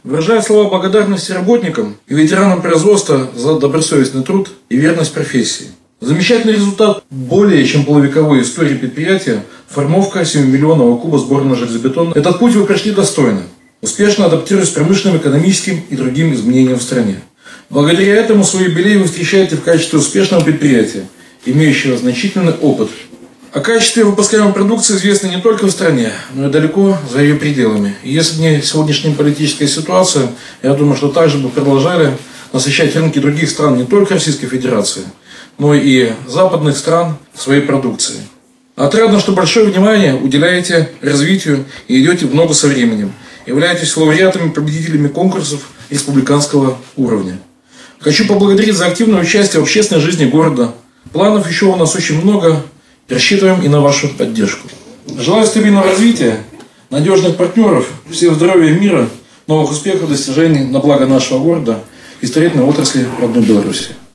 Торжественное мероприятие «Ценить человека. Гордиться предприятием. Любить Родину» состоялось 10 февраля.
Тёплые слова пожеланий адресовал заводчанам глава города Михаил Баценко.